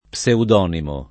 vai all'elenco alfabetico delle voci ingrandisci il carattere 100% rimpicciolisci il carattere stampa invia tramite posta elettronica codividi su Facebook pseudonimo [ p S eud 0 nimo ] agg. e s. m. — cfr. toponomastico